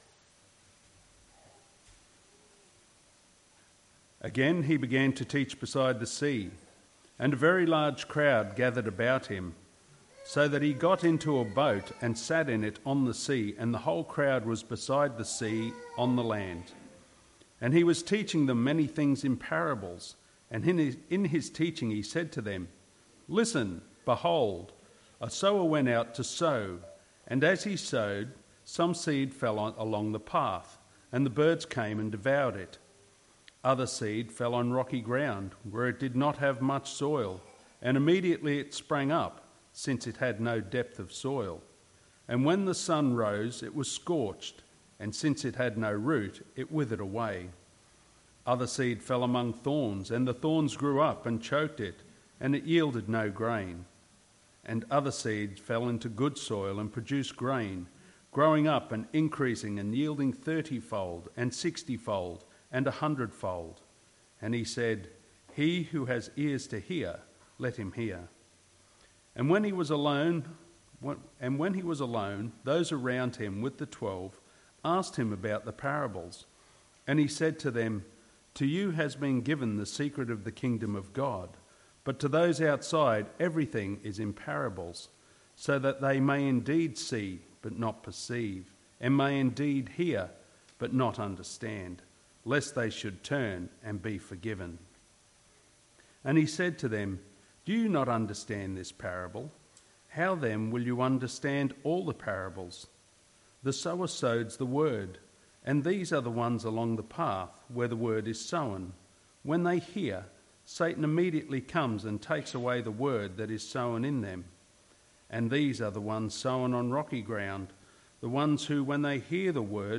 Romans | Sermon Books | Christian Reformation Community Church